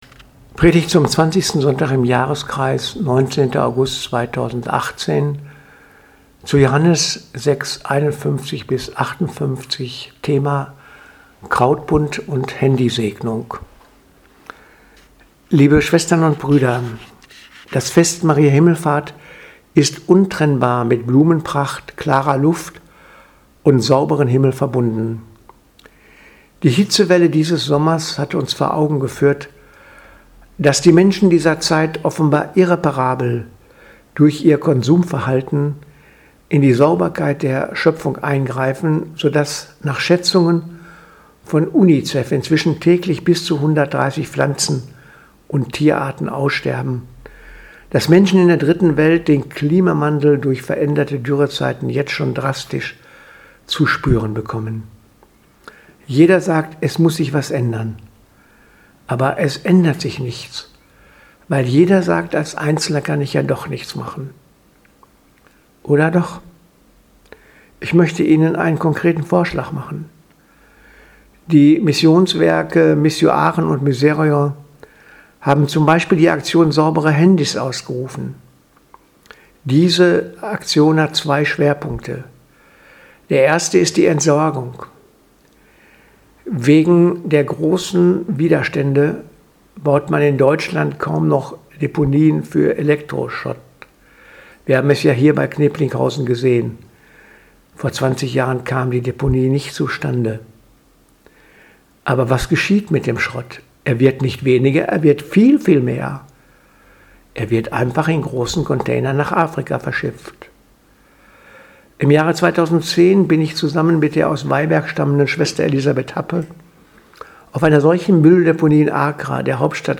Predigt vom 19.08.2018 – Handysegnung